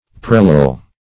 Search Result for " prelal" : The Collaborative International Dictionary of English v.0.48: Prelal \Pre"lal\, a. [L. prelum a press.]